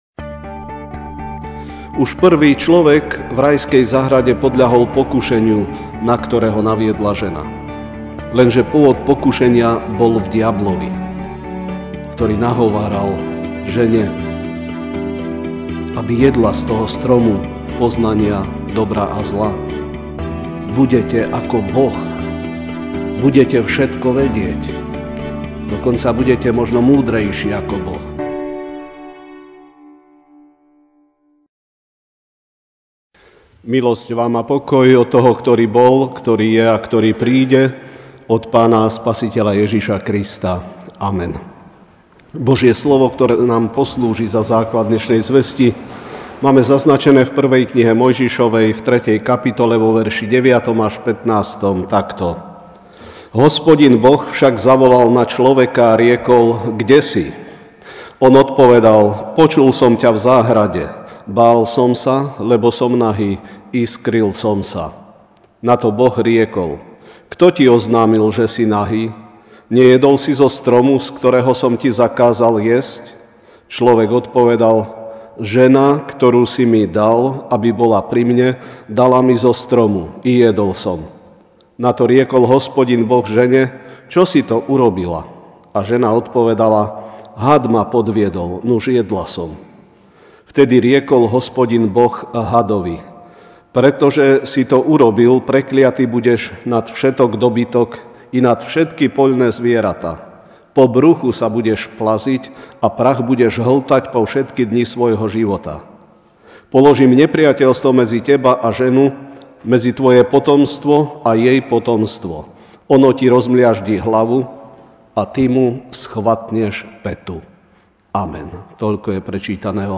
dec 16, 2018 Hriech človeka MP3 SUBSCRIBE on iTunes(Podcast) Notes Sermons in this Series Večerná kázeň – Hriech človeka (1M 3, 9-15) Hospodin Boh však zavolal na človeka a riekol: Kde si?